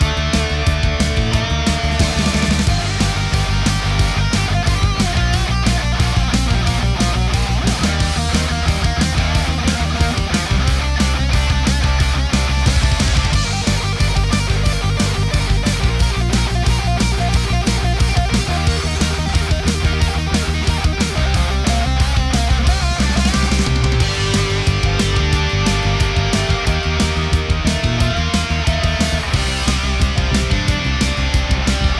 Гитарный звук через ламповый преамп + симулятор кабинета с импульсом